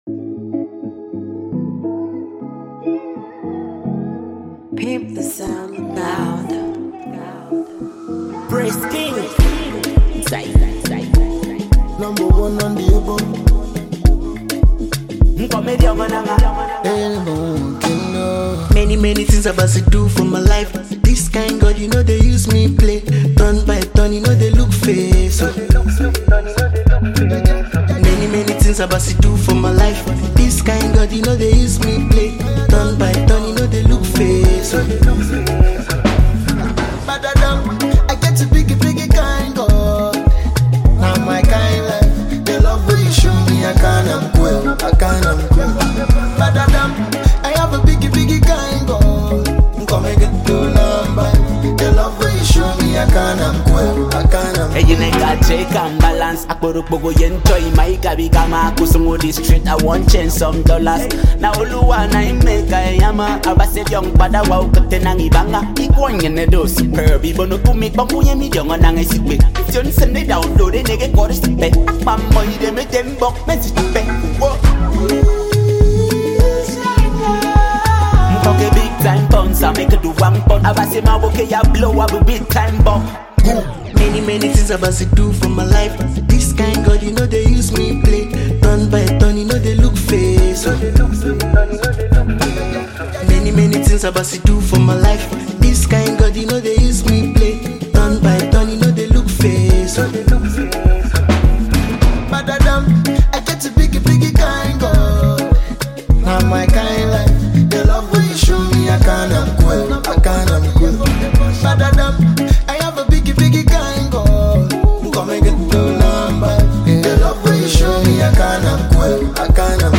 Emerging gospel-pop music artiste
rappers